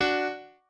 piano4_17.ogg